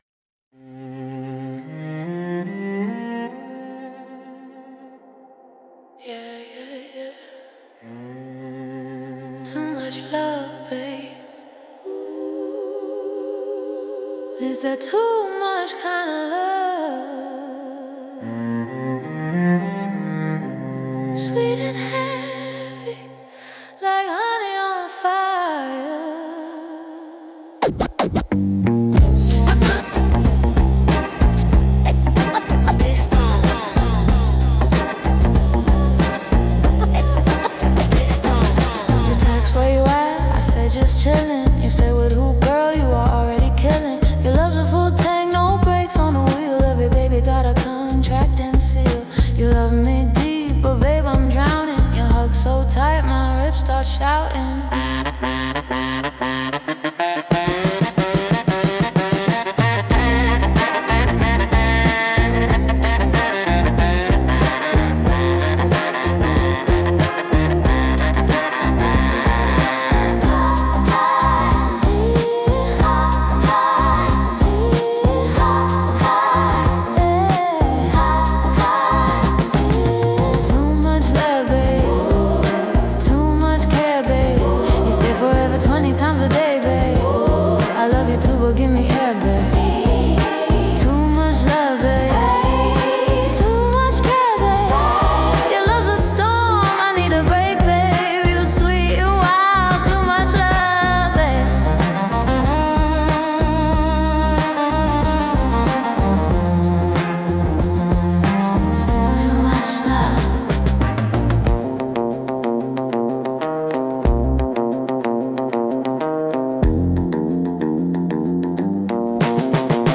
The song “Too Much Love, Babe” is a lighthearted hip-hop track about a relationship where love, affection, and care become excessive and overwhelming. Despite its joyful beat and playful slang, the lyrics explore the tension between deep emotional connection and the need for personal space.
The tone is humorous and ironic, using street language, repetition, and sharp rhymes to make the theme entertaining rather than dramatic. Overall, it’s a happy, rhythmic portrayal of love that’s both sweet and exhausting.